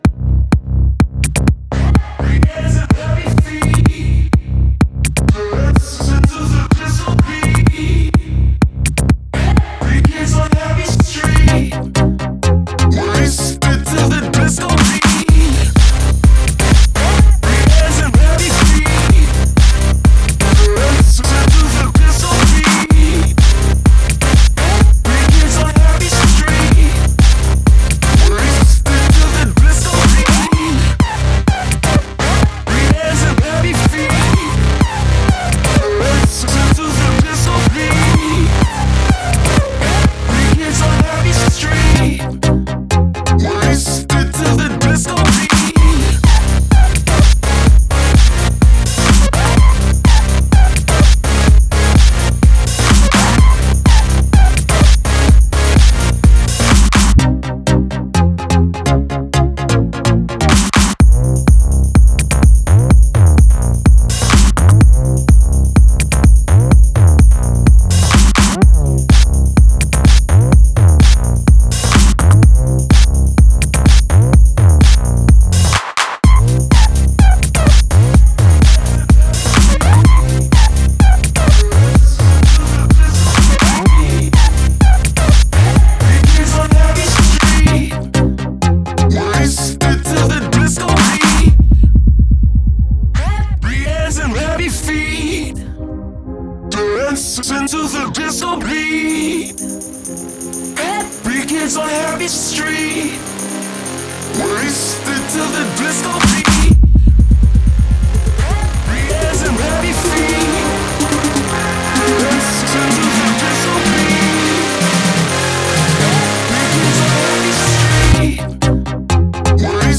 Жанр:Electronic